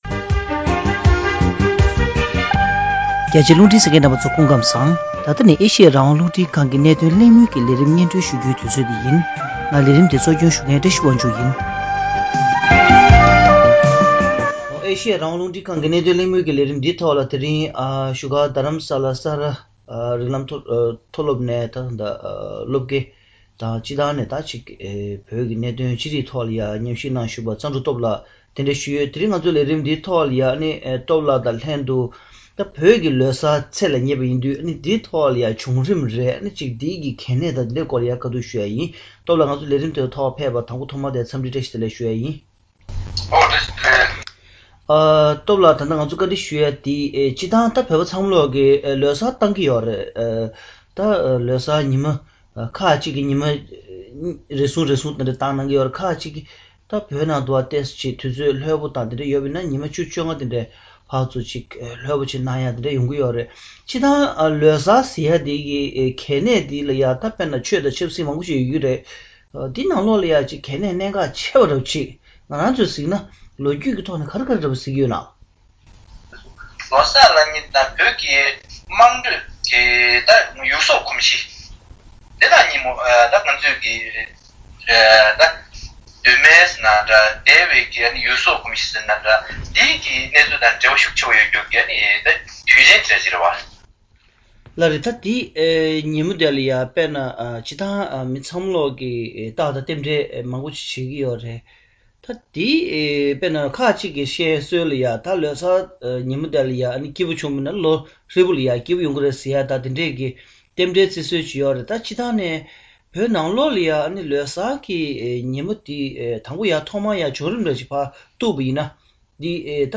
བོད་ཀྱི་ལོ་གསར་སྲུང་བརྩི་ཞུ་ཕྱོགས་དང་དེ་དག་གི་གལ་གནད་སྐོར་ལ་གླེང་མོལ་ཞུས་པ།